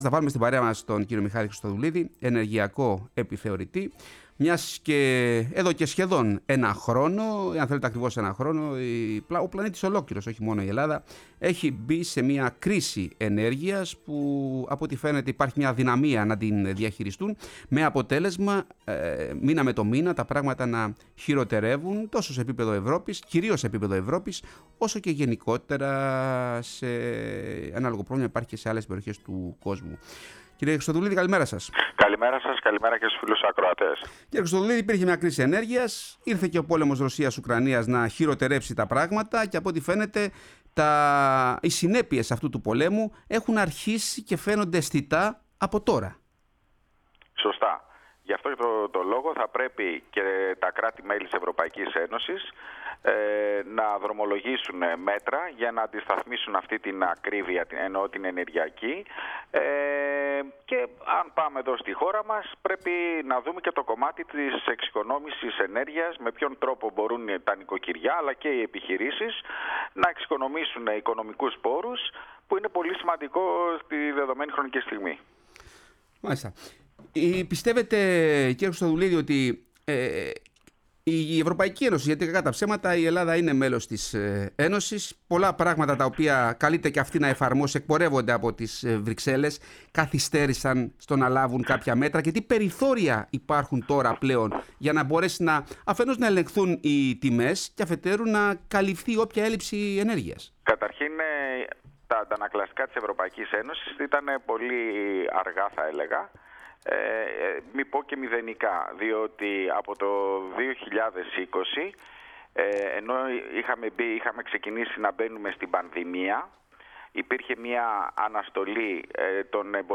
Συνεντεύξεις